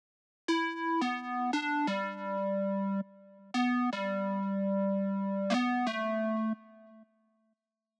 34 Square Synth PT1.wav